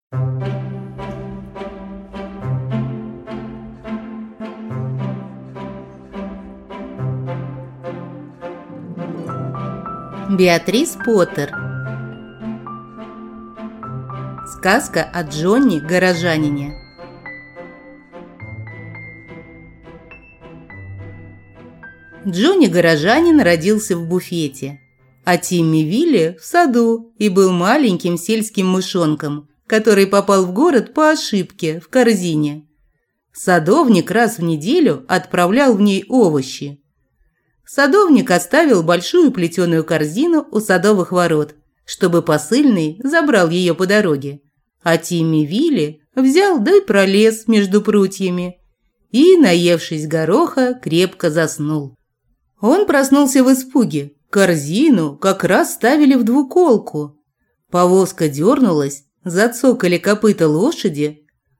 Аудиокнига Сказка о Джонни Горожанине | Библиотека аудиокниг